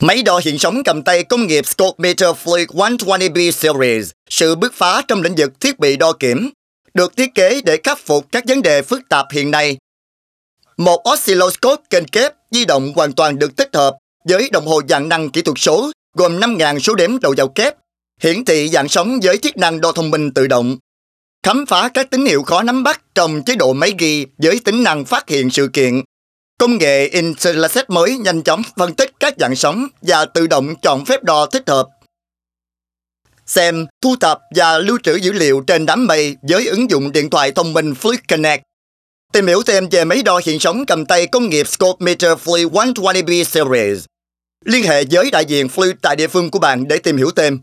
I have a rich, warm, deep and unique voice
Sprechprobe: Werbung (Muttersprache):